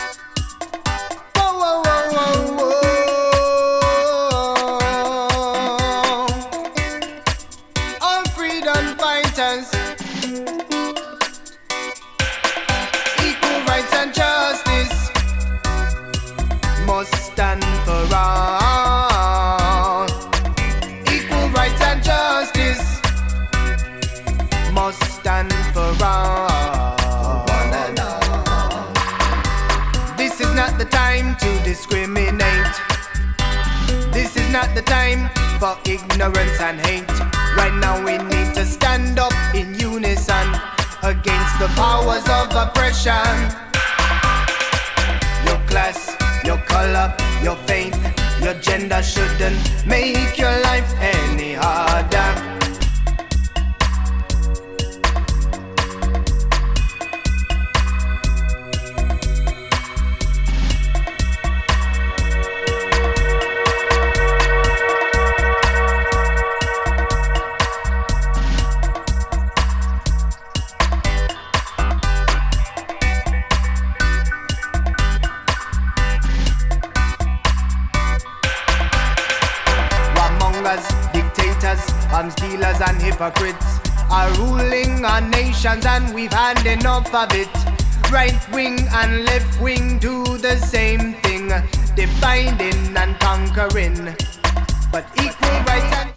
2. Dub